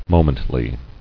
[mo·ment·ly]